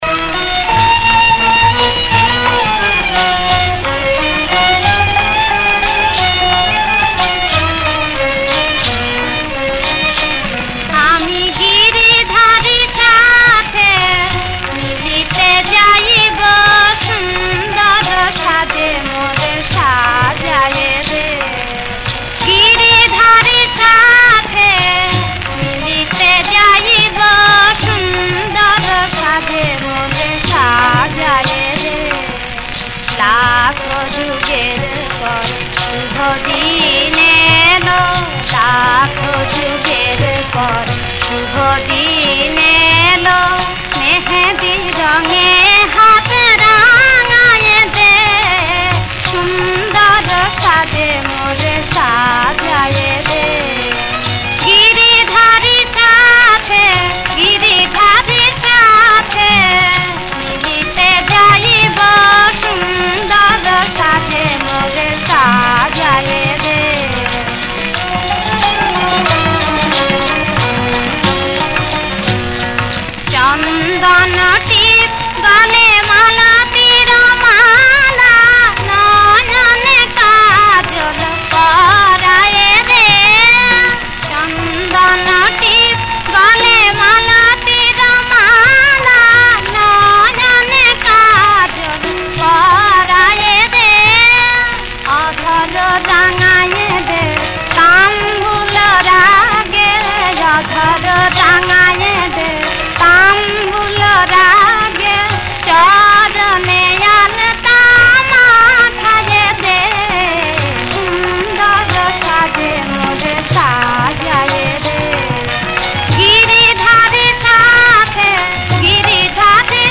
ভজন।